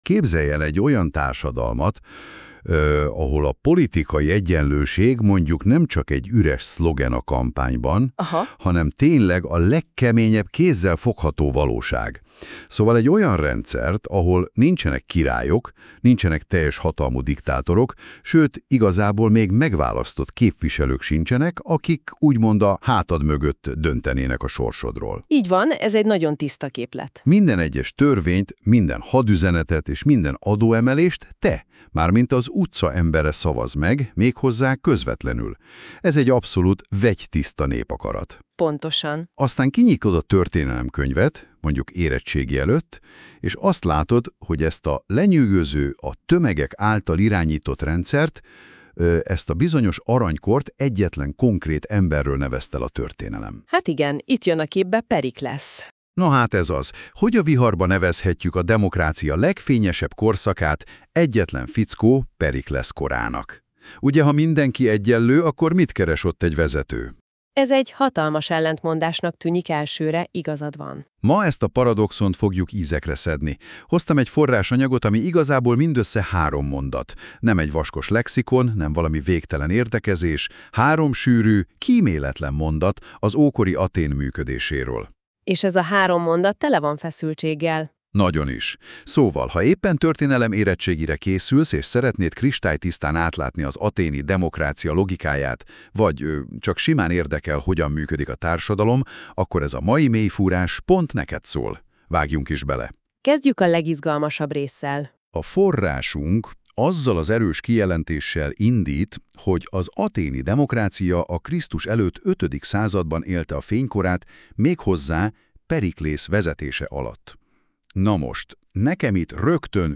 Két házigazda beszélgetése magyarul.